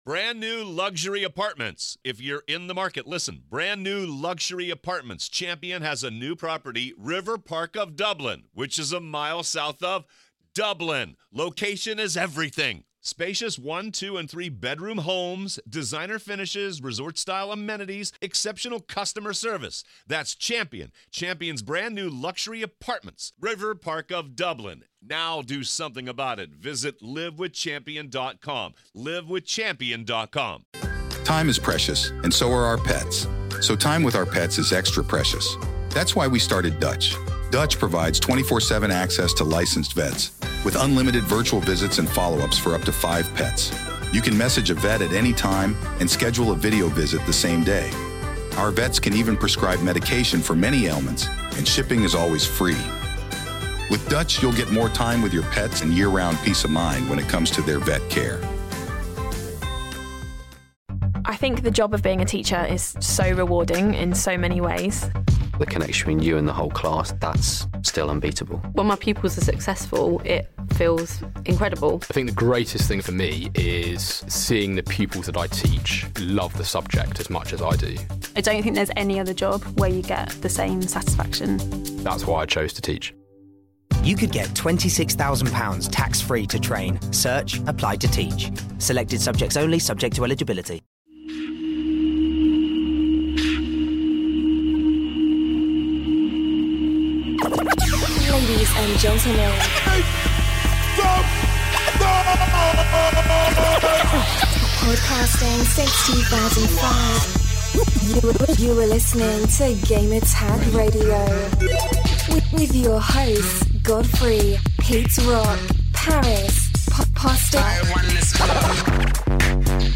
We answer listener questions and interview